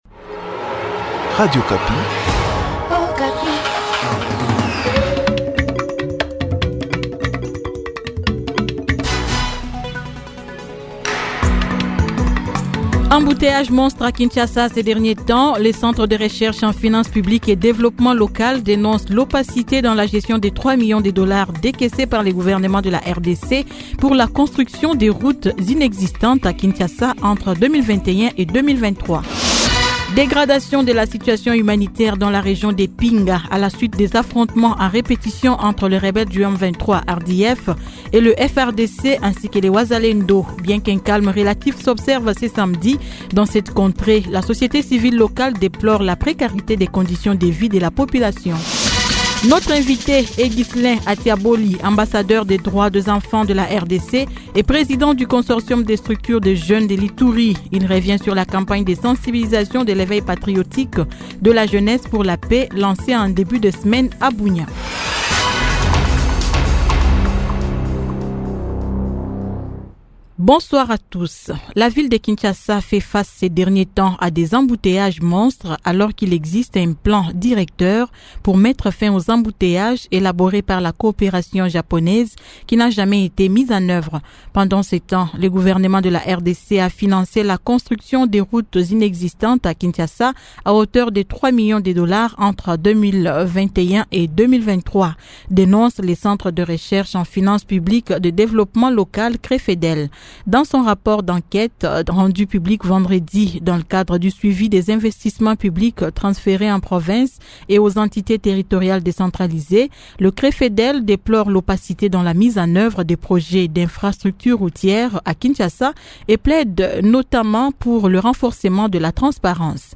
Journal Soir 18H00